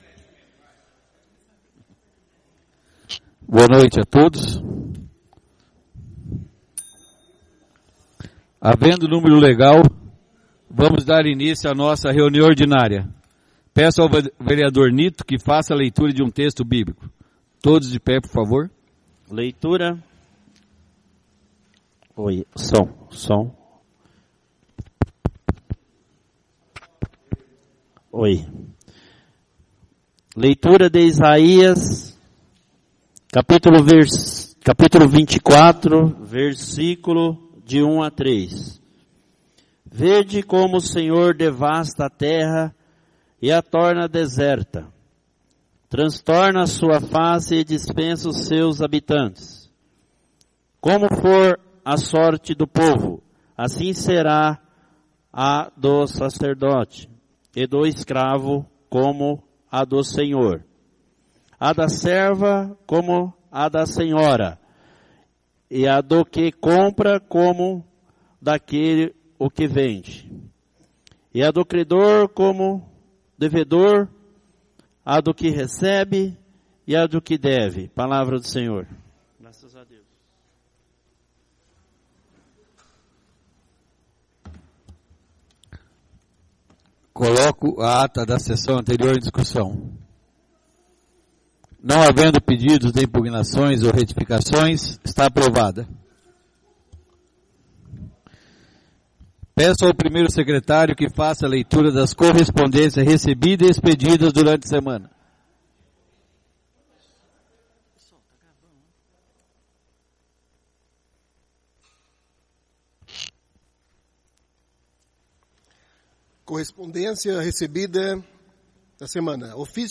O Presidente da Câmara Municipal de Sarandi-Pr Sr. Carlos Roberto Falaschi, verificando a existência de quórum legal dá início à 20ª Reunião Ordinária do dia 19/06/2017.
A convite do Senhor Presidente, o edil JOSÉ APARECIDO DA SILVA procedeu à leitura de um texto bíblico.